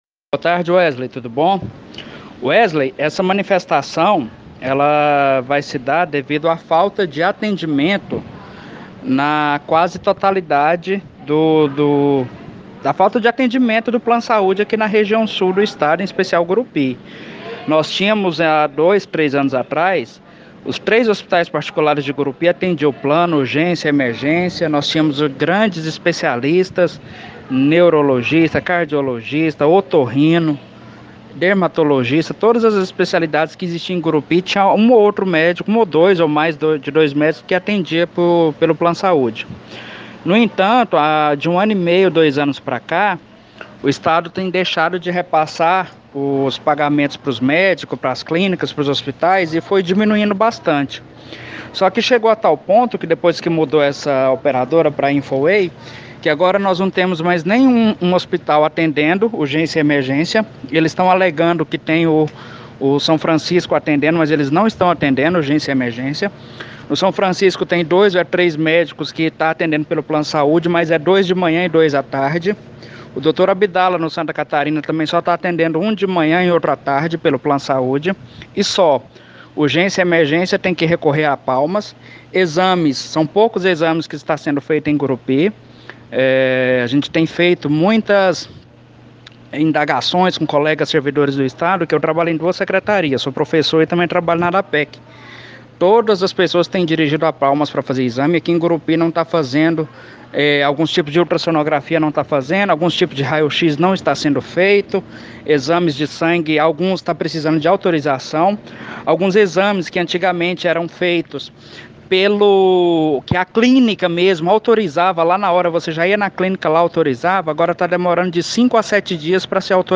Entrevista-Plansaúde-online-audio-converter.com_.mp3